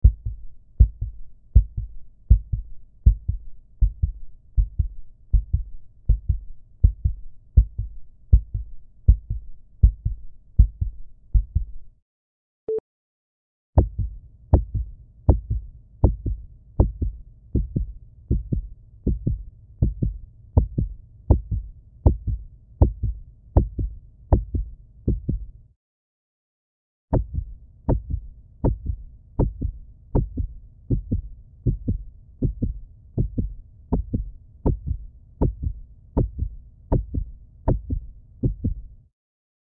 Heartbeat Manipulation
One option to increase the high frequency content (to make the heartbeat more audible) is to use the vocoder to mix your heartbeat with noise, e.g. attached. The result is like wooshy Doppler ultrasound …